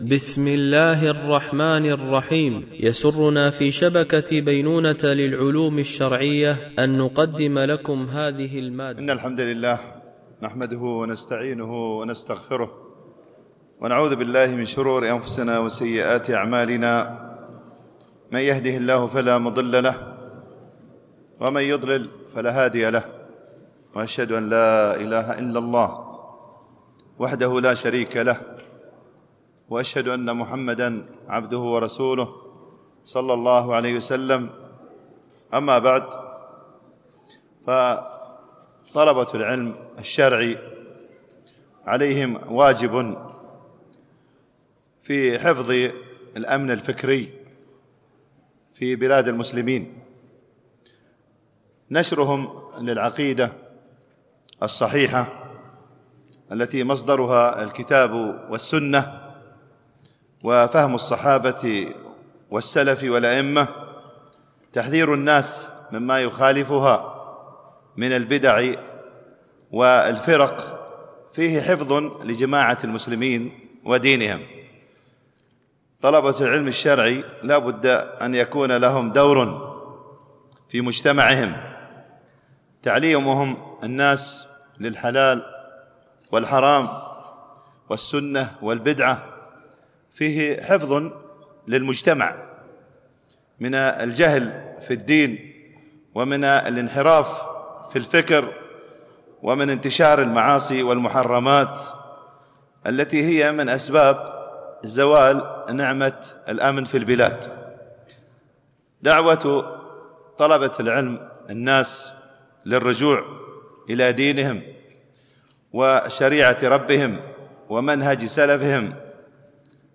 شرح كتاب فضائل القرآن من مختصر صحيح البخاري ـ الدرس 04
الدورة: دورة الإمام مالك العلمية العاشرة، بدبي